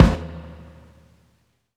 stacktom_kick.wav